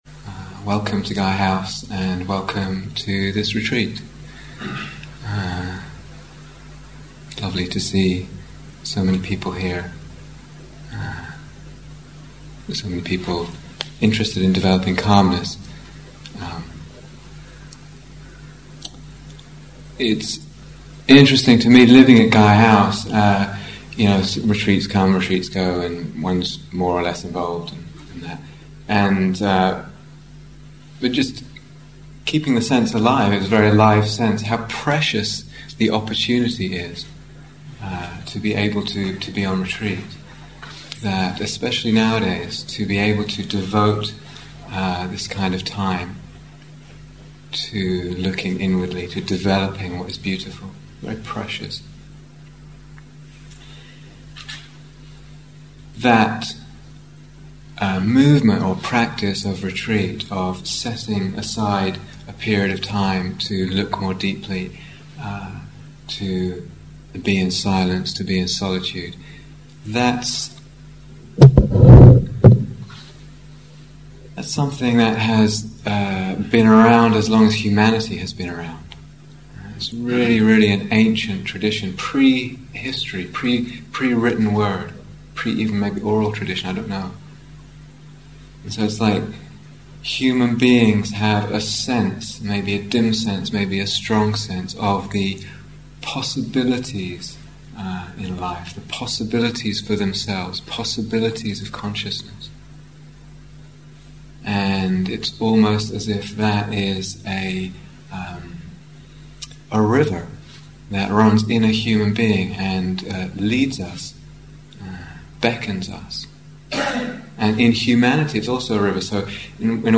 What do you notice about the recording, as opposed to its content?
Opening Talk on Samatha (Concentration Practice) Download 0:00:00 40:00 Date 30th March 2007 Retreat/Series Samatha Meditation Transcription Welcome to Gaia House, and welcome to this retreat.